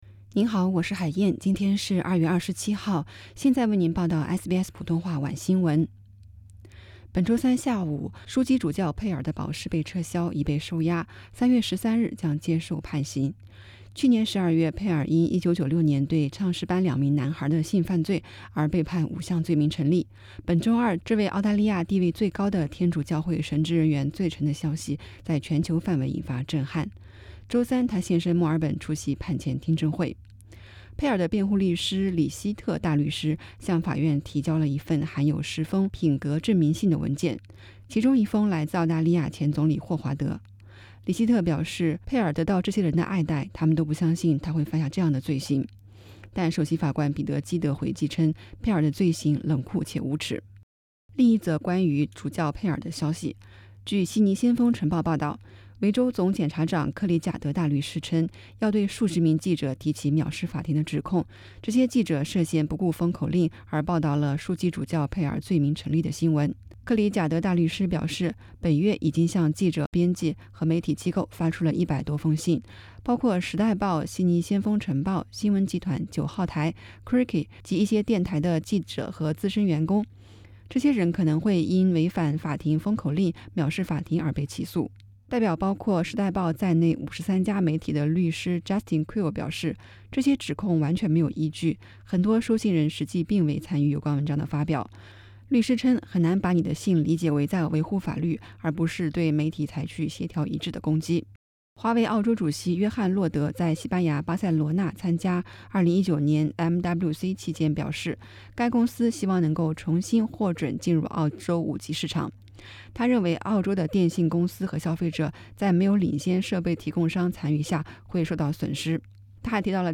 SBS晚新闻（2月27日）